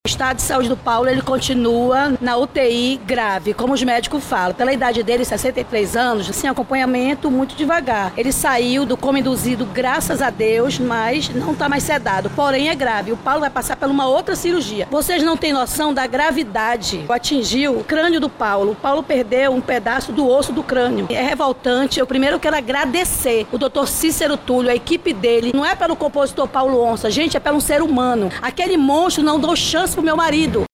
A entrevista coletiva ocorreu nessa segunda-feira 09/12, na sede da Delegacia Geral da Polícia Civil do Amazonas, localizada na zona Centro-Oeste de Manaus.